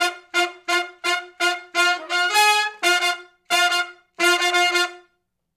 065 Funk Riff (F) uni.wav